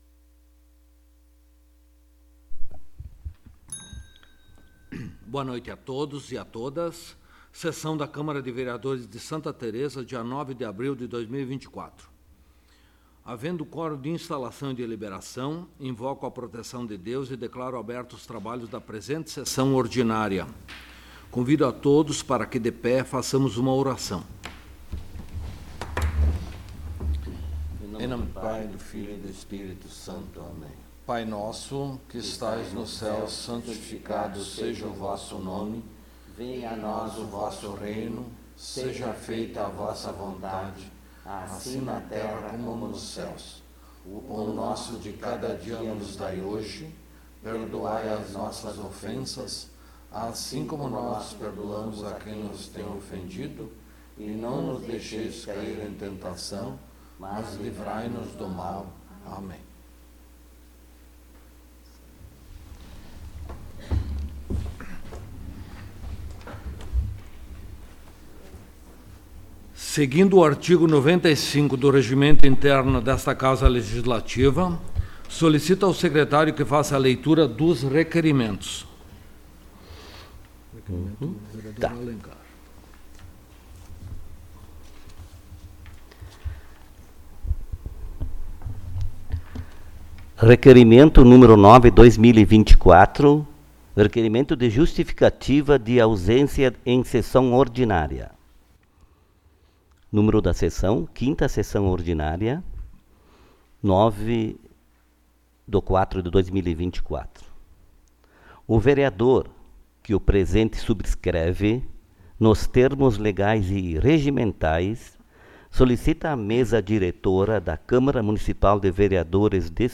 5ª Sessão Ordinária de 2024
Local: Câmara Municipal de Vereadores de Santa Tereza